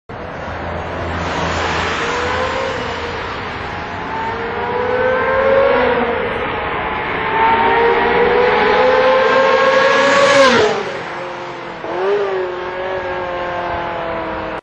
バルブを閉めるとアイドリング音量は３〜４割程度静かに
通過音１ /
装着車の走行音です。